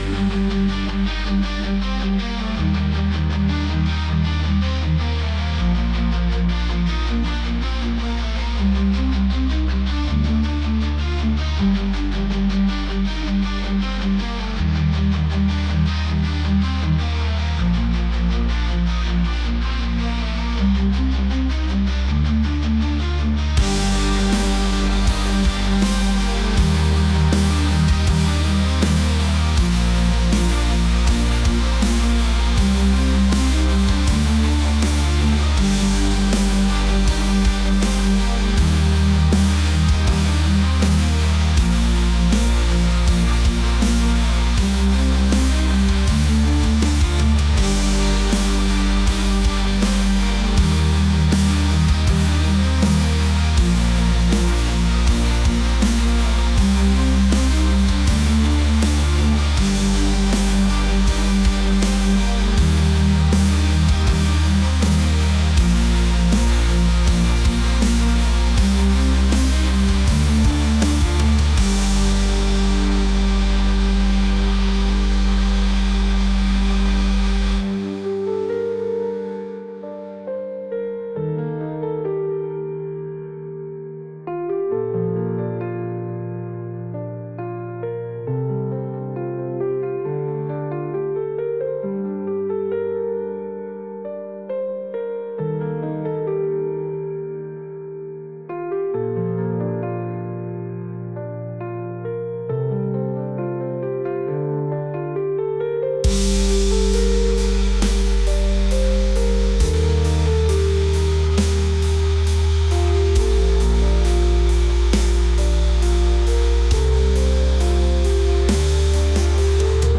cinematic | ambient | rock